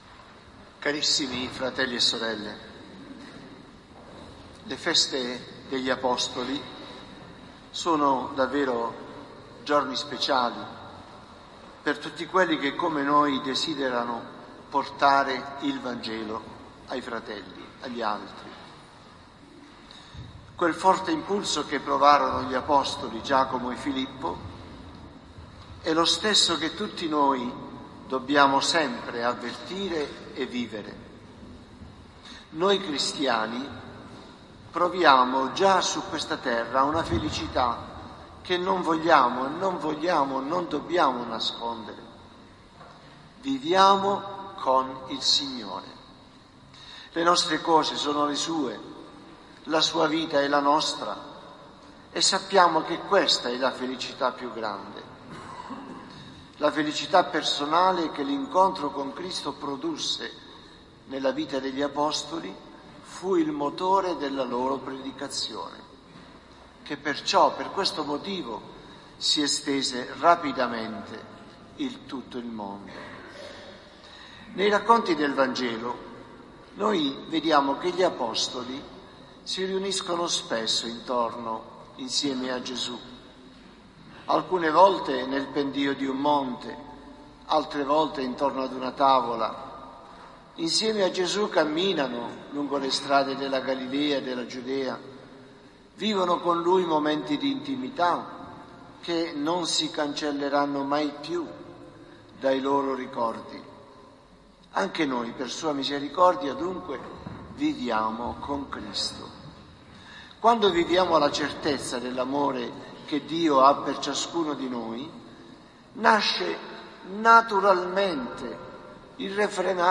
Omelia del Vescovo https